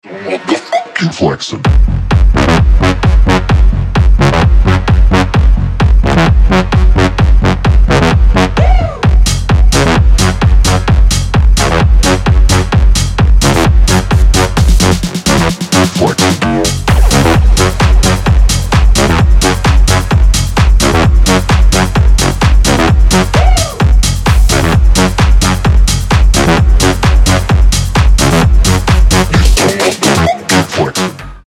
• Качество: 320, Stereo
Electronic
качающие
electro house
mainstage music